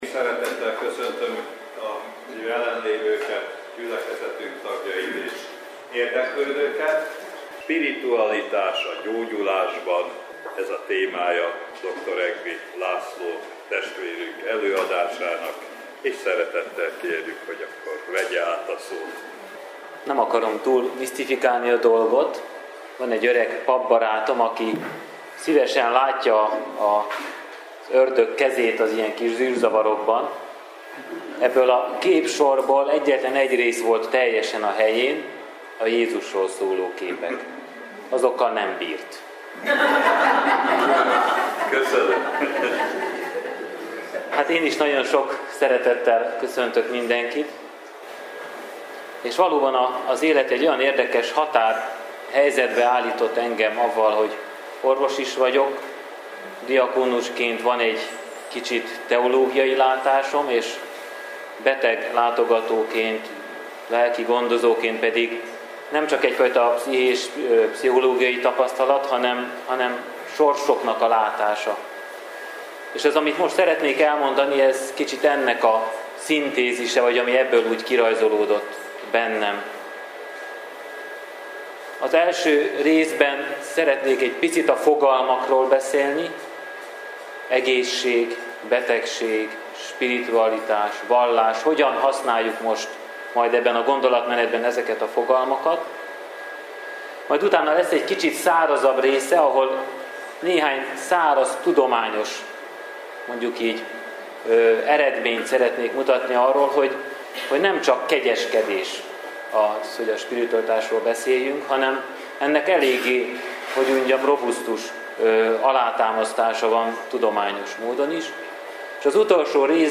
előadása